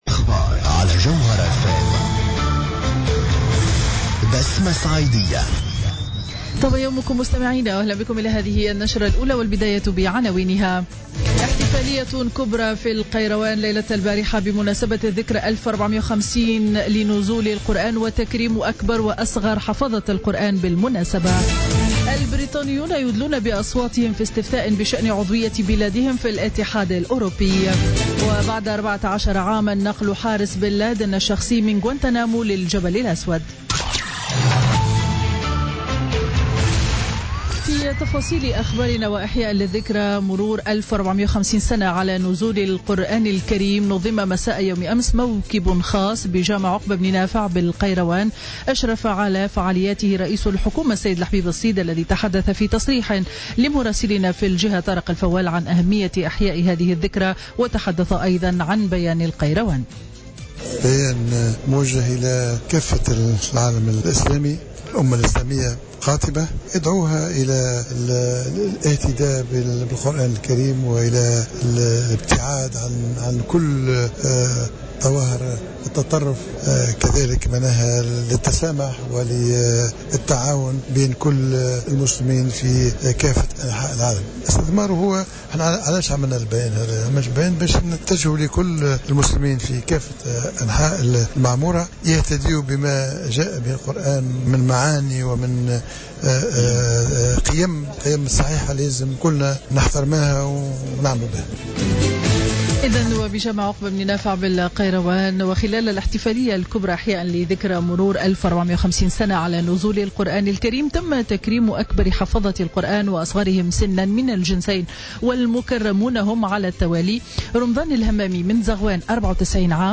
نشرة أخبار السابعة صباحا ليوم الخميس 23 جوان 2016